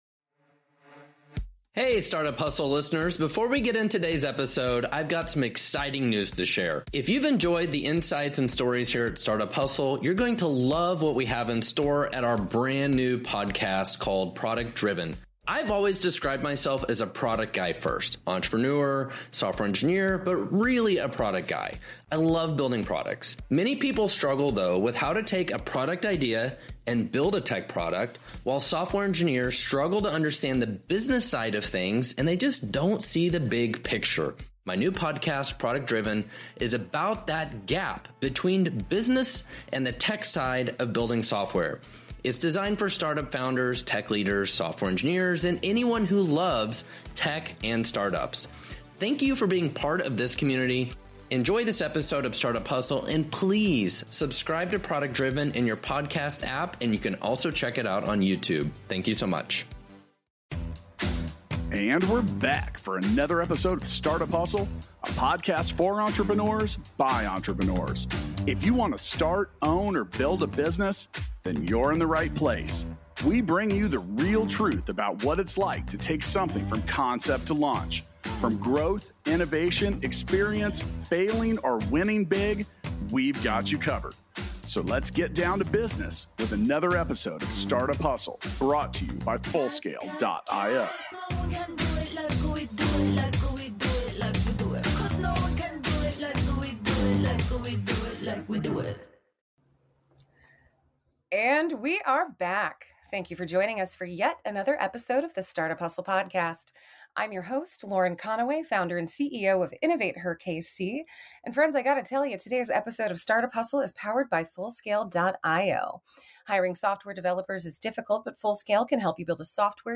in a conversation about mastering the delicate balance between hustle and gratitude.